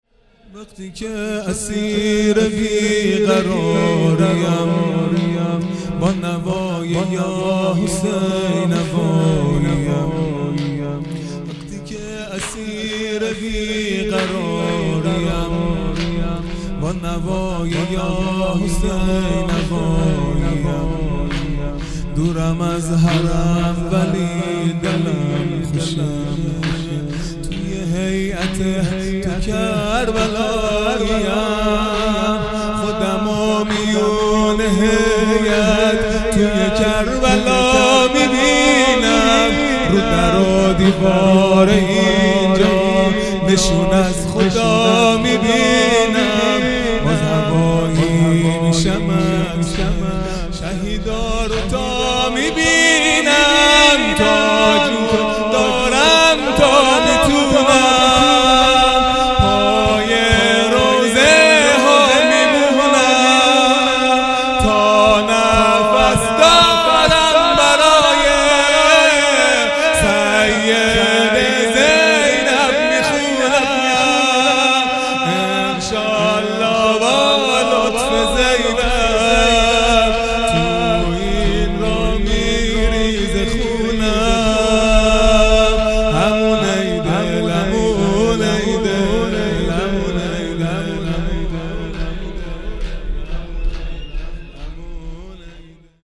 خیمه گاه - هیئت بچه های فاطمه (س) - شور پایانی | وقتی که اسیر بی قراریم